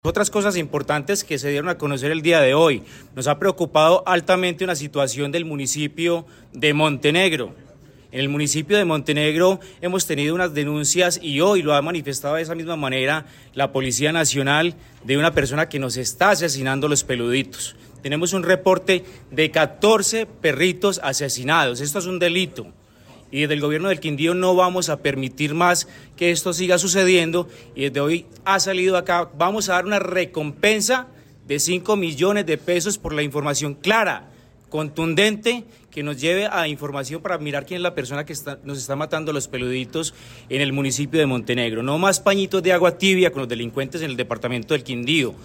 “No más pañitos de agua tibia contra los delincuentes en el departamento del Quindío”: Secretario del Interior
Secretario del Interior, Diego Alexander Santamaría, Macotas asesinadas